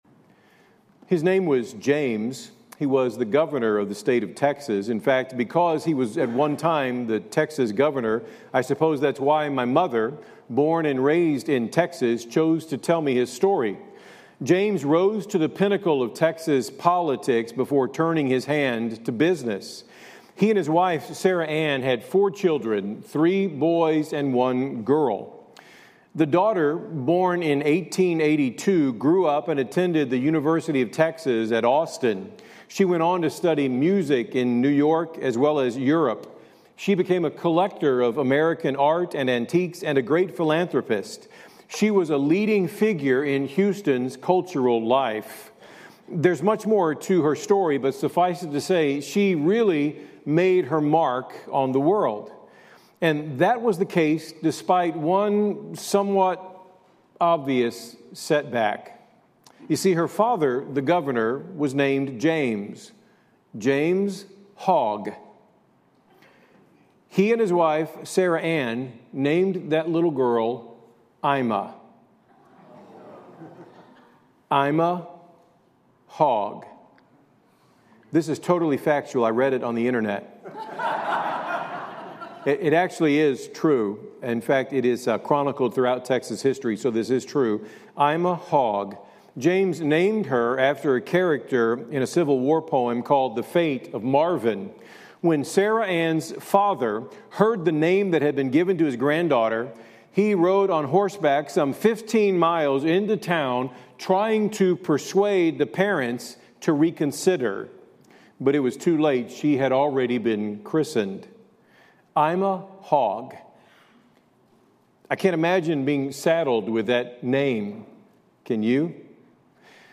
Sermons | First Baptist Church
First Baptist Church Kearney MO -Easter Sunday Morning Worship, April 20, 2025